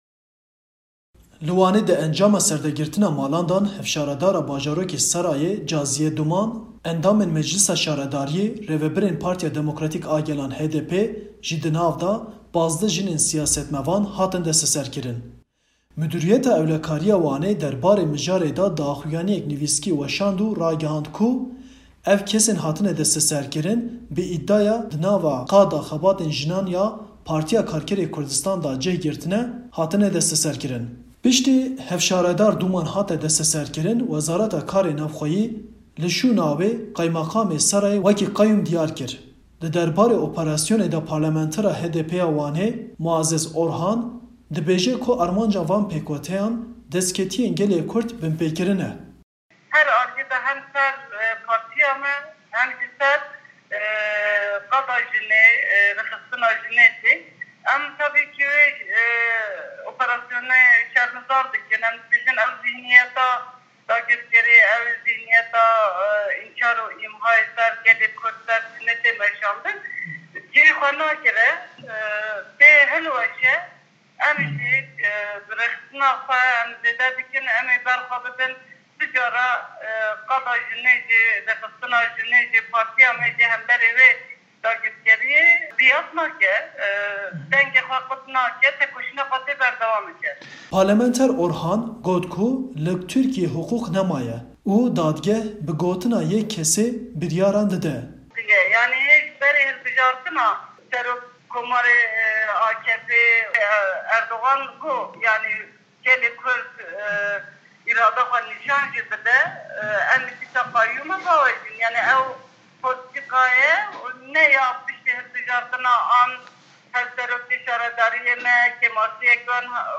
Derbarê operesyona li henber sîyasetmevanên jin û dîyar kirina qeyuman de Parlamantera HDPê ya Wanê Muazzez Orhan dibêje ku armanca van pêkutîyan, binpekirina destkeftîyen gelê Kurd e.